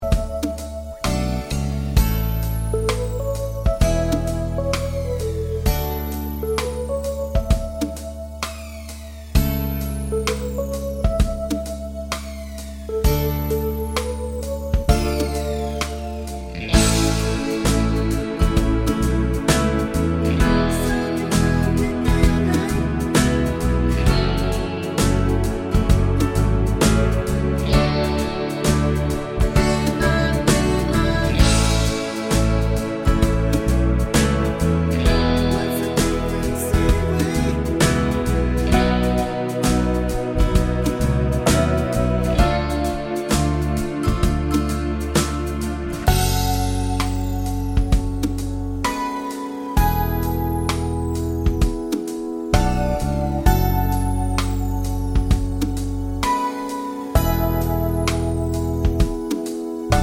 Without Intro Fade Pop (1990s) 4:21 Buy £1.50